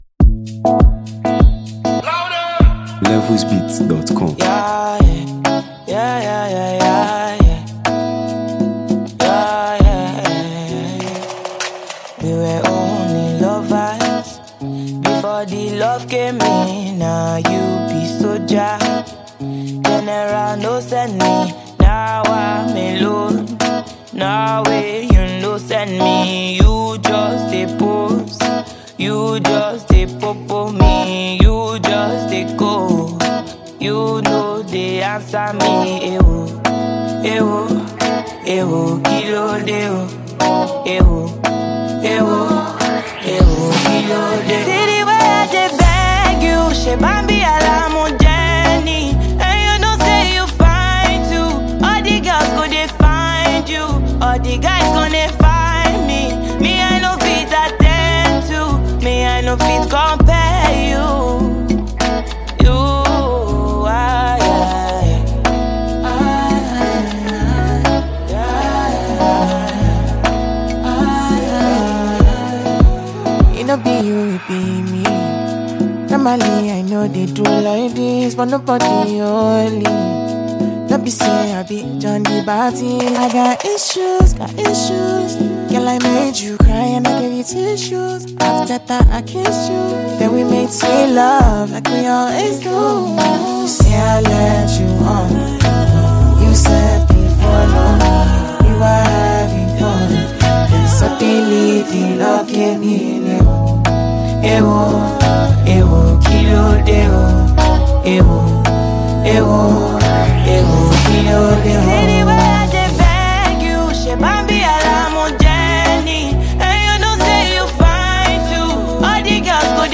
Nigeria Music
signature soulful delivery